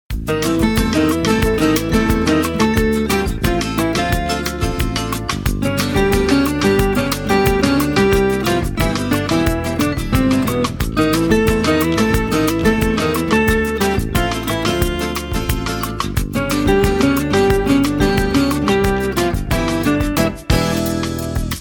• Качество: 187, Stereo
инструментальные
мелодия